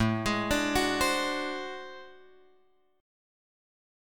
A Minor Major 13th